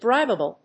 音節brib・a・ble 発音記号・読み方
/brάɪbəbl(米国英語)/